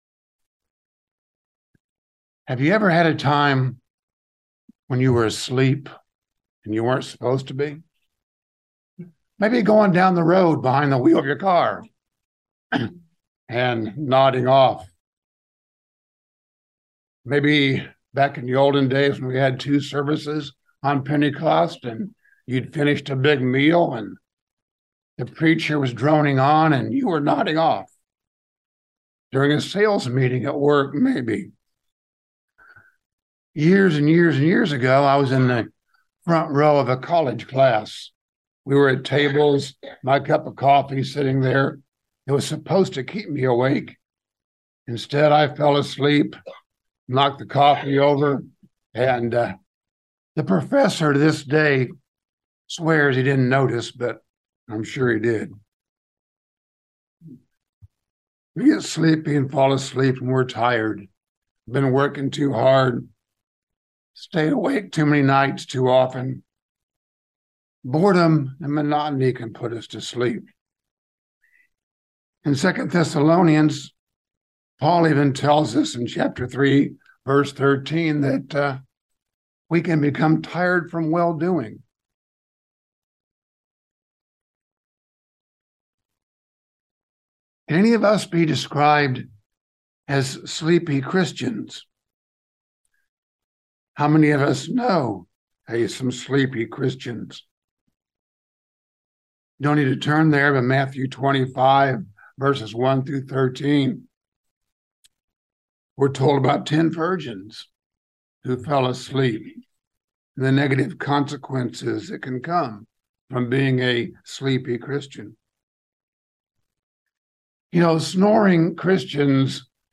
A message examining the contrast of a Christian being “drunk with wine” or being “filled with the Spirit” to enable us to do God’s will in our lives.
Given in London, KY